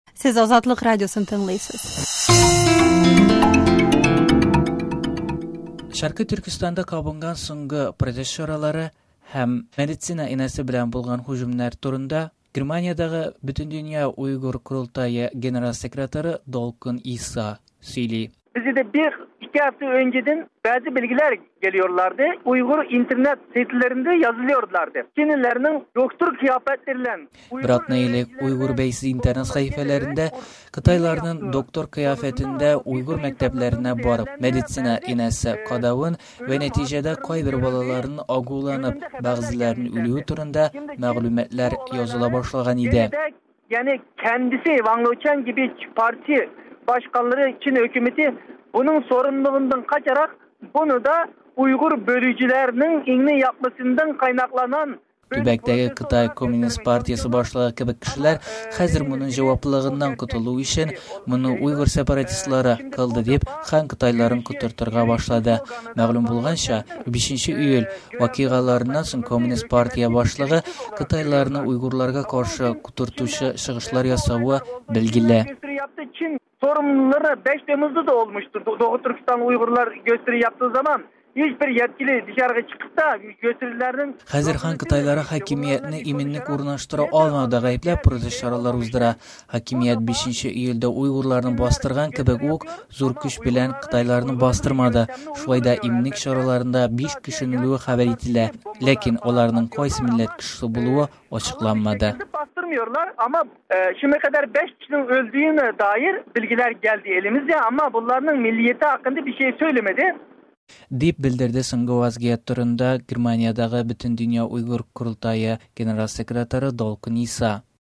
Öremçedäge soñgı protest häm enäle höcüm turında Bötendönya uygır qorıltayı urınbasarı Dulqın Isa belän äñgämä